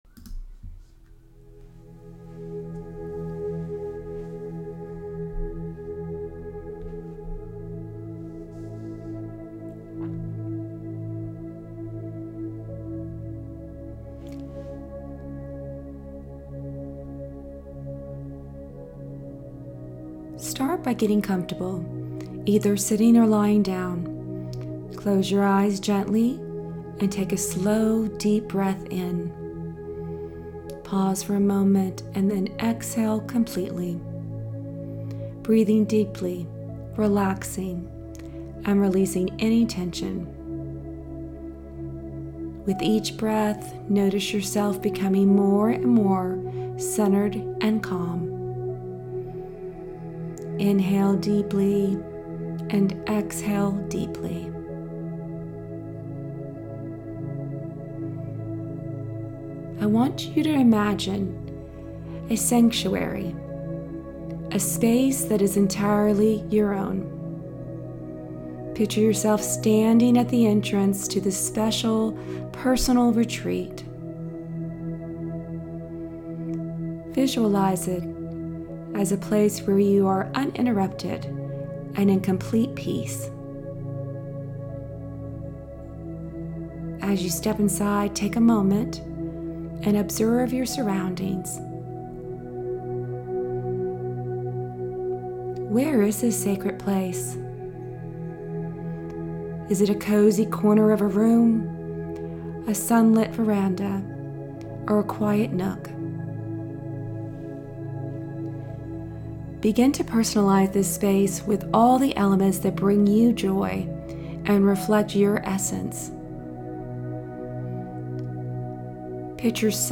Chapter 4 Guided Meditation.
Chapter-4-Guided-Meditation.-Sacred-Space.-5.54-min.-2.m4a